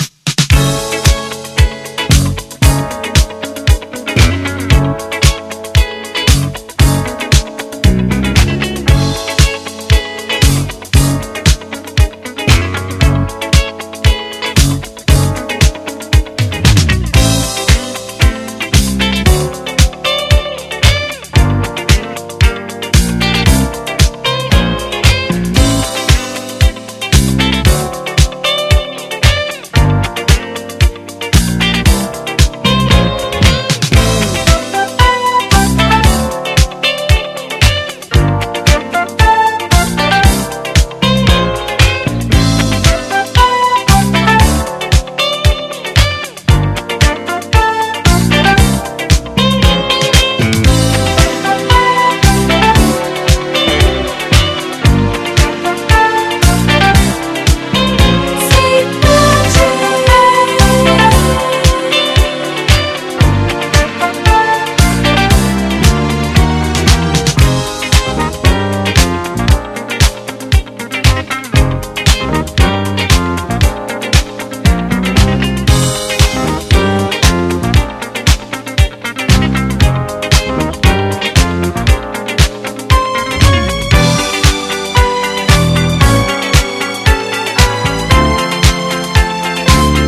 モロ使いされたグルーヴ、そして美しいエレピも完璧な極上メロウ・グルーヴです
ドカドカと激しくビートが打ちつける